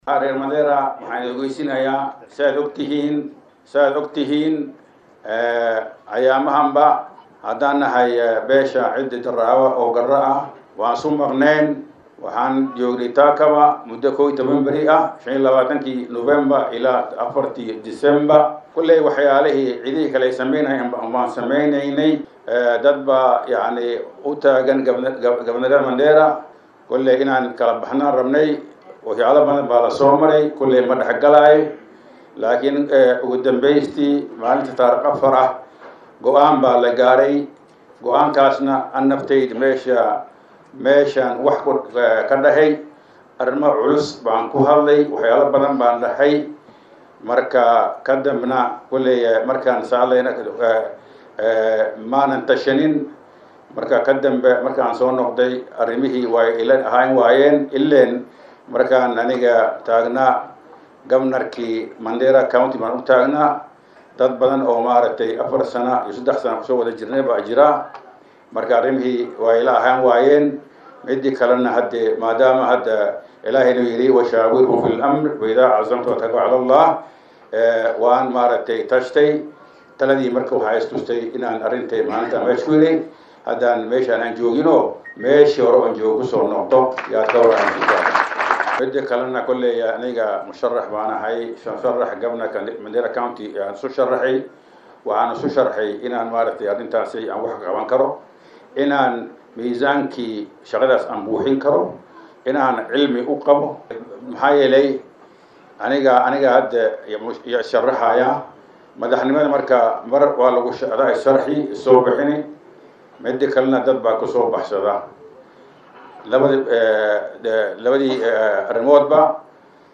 DHAGEYSO:Senetarka Mandera oo ka hadlay moqifkiisa doorashada guud ee 2022-ka
Mumad oo maanta xaflaad kuqabtaay magalada nairobi ayaa shaciyay inu kamd noqoni donoo Musharaxiinta u tartamayo kursiga barsabaaka Ismamulka Mandera.